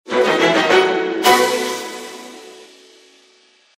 Message tone 07.mp3